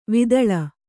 ♪ vidaḷa